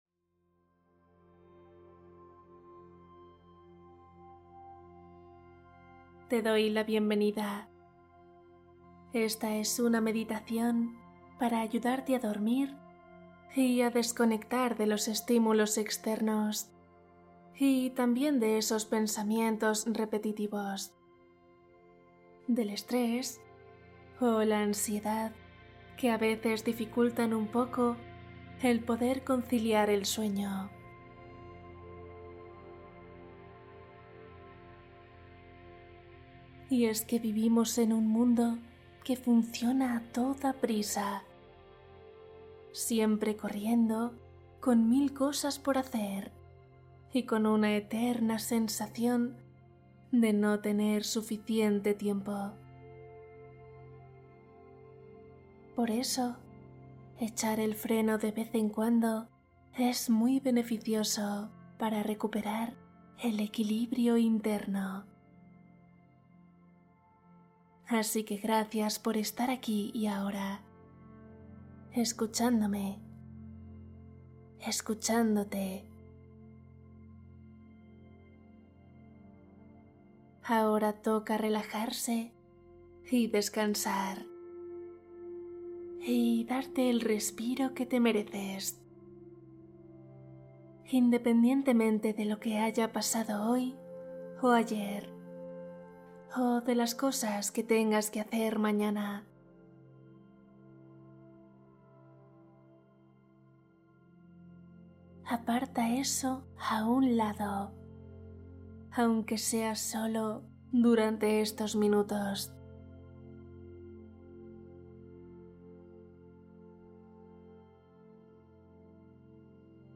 Cuento y meditación para dormir feliz | Relajación nocturna para soñar bonito